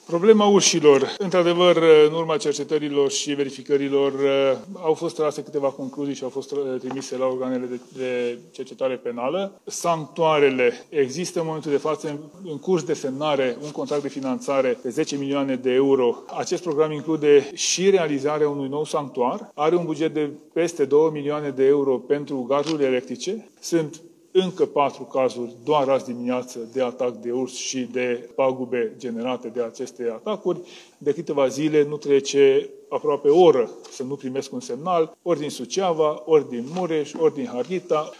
Ministrul Tanczos Barna a fost întrebat astăzi ce poate face pentru blocarea braconajului, care are loc cu sprijinul unor angajați ai statului.